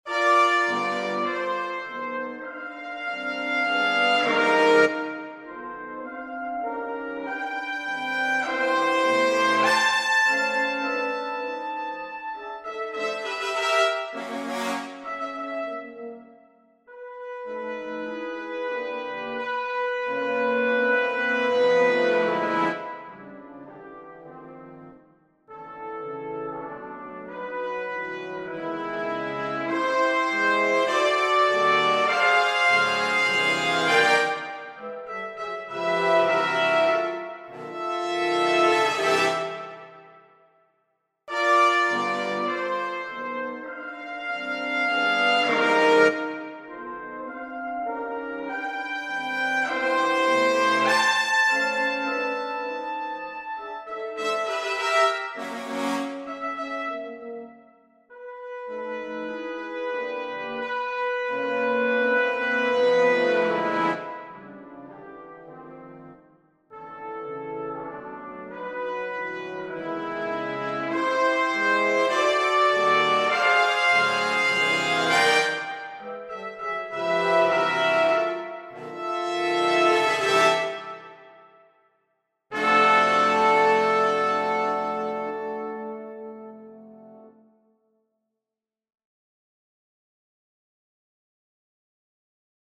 Persichetti Exercise 7 - 47 for Brass Sextet
Harmonize the following first-trumpet melody in six part brass harmony (three trumpets and three trombones). Use a predominantly polychordal texture with occasional unis...